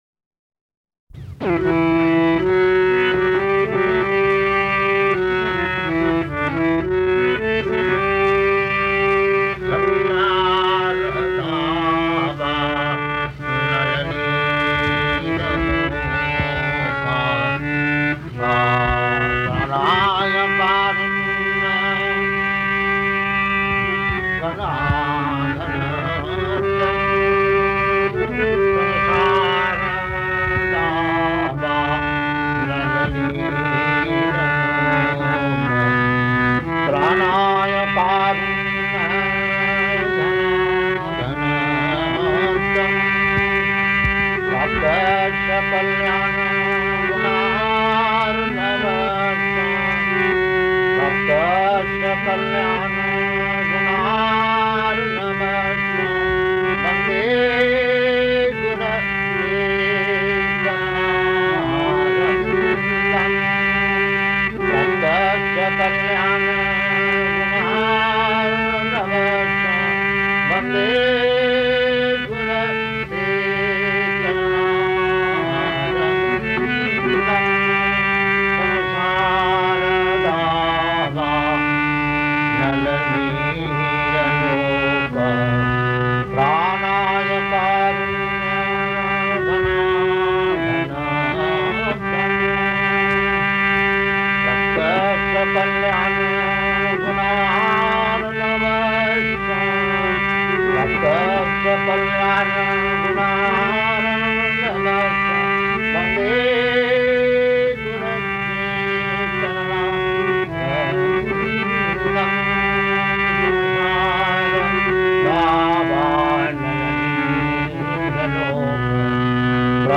Bhajan & Purport to Śrī-Śrī-Gurv-aṣṭakam
Type: Purport
Location: Los Angeles
Prabhupāda: [sings:]